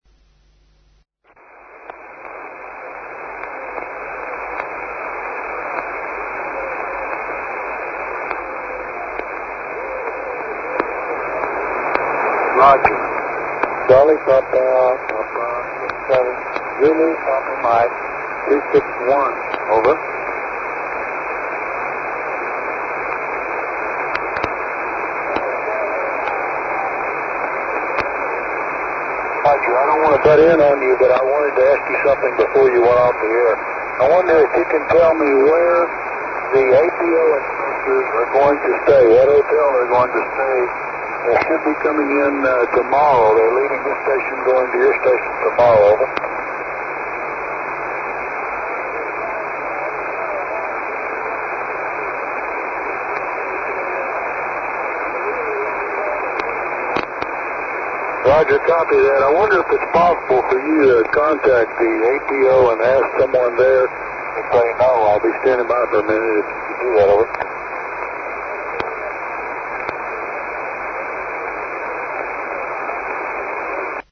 listen here to ZPM261 (strong) working with CPP67 (very weak)